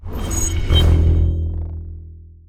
x1_battle_xiaobang_skill_01.wav